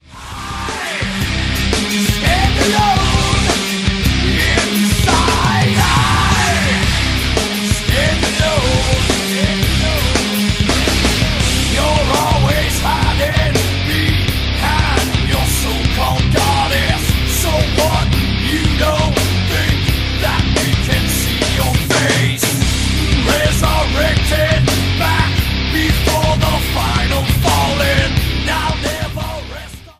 Voilà, donc la subtilité, c’est pour les tatas !